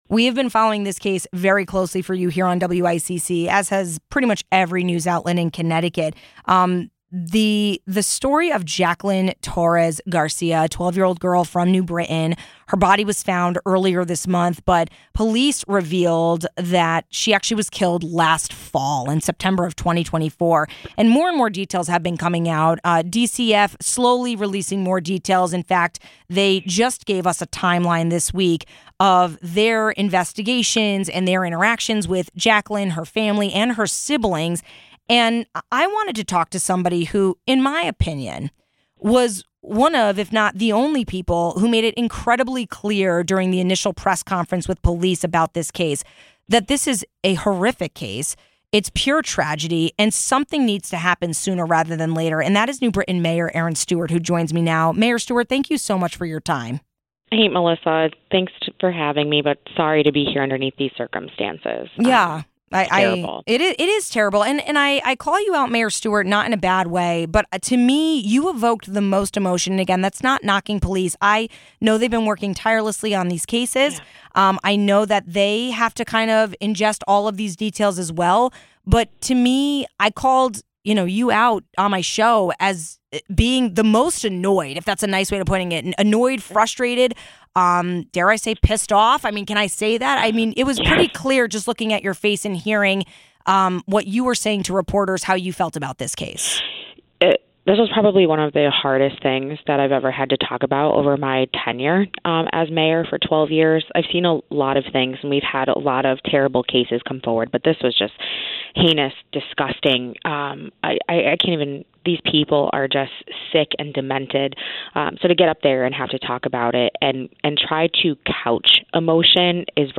What needs to change with DCF and others following this tragedy? We spoke with New Britain Mayor Erin Stewart about it.